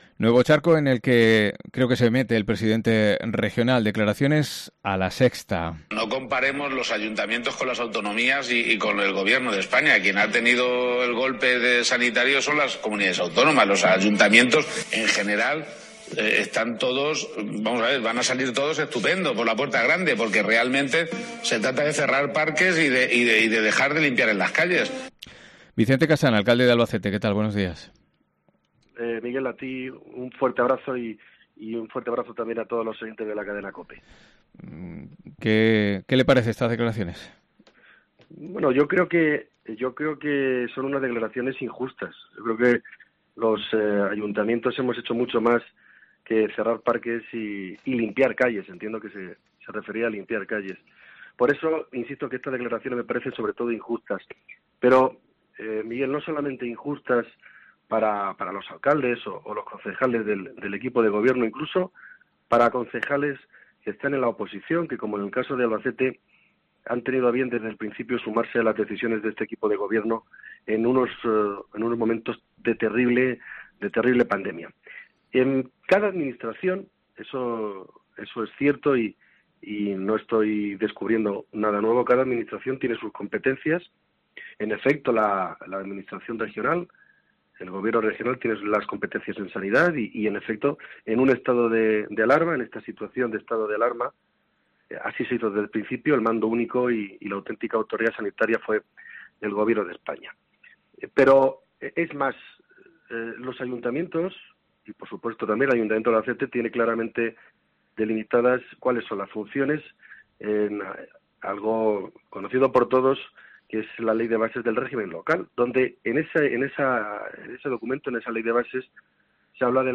AUDIO: Vicente Casañ responde en COPE Albacete a las declaraciones de Emiliano García Page en las que aseguraba que los Ayuntamientos económicamente...
ENTREVISTA COPE